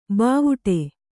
♪ bāvu'ṭe